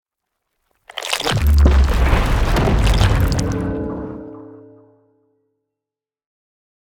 / assets / minecraft / sounds / mob / warden / dig.ogg
dig.ogg